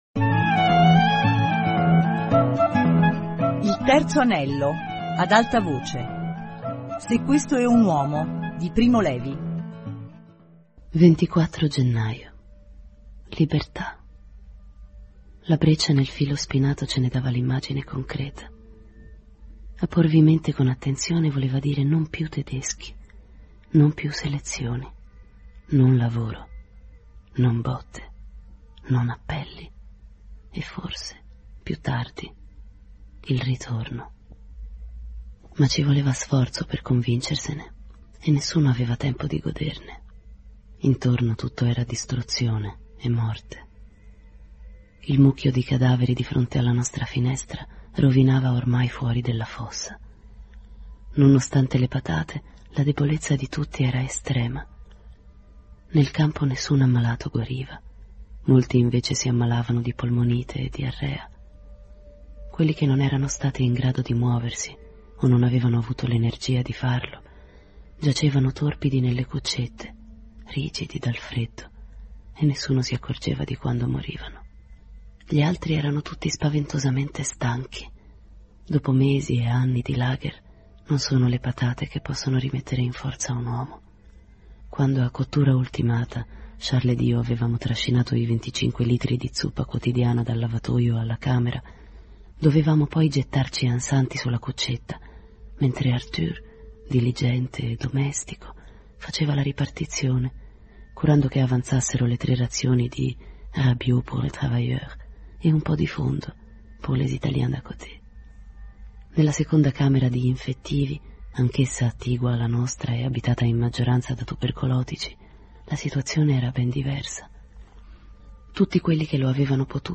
Se questo é un uomo - Lettura XXII